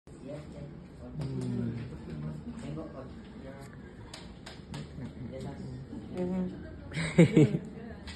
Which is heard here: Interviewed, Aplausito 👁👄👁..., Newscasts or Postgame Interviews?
Aplausito 👁👄👁...